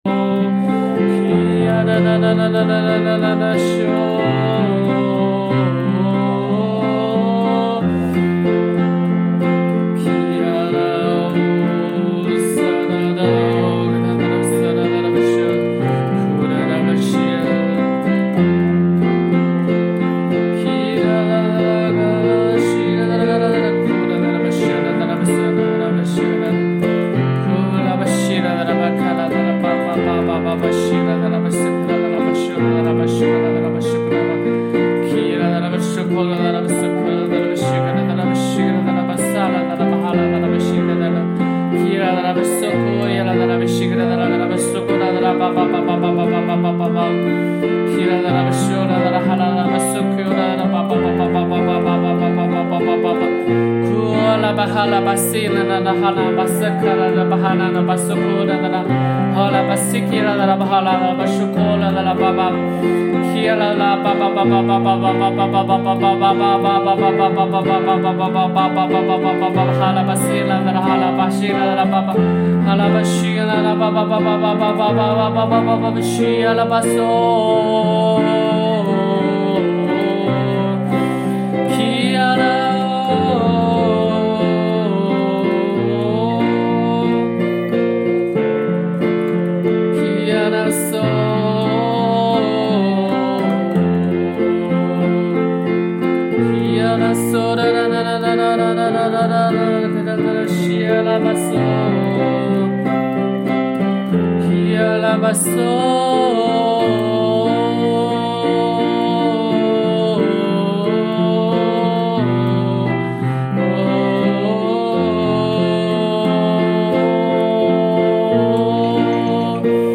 启示性祷告：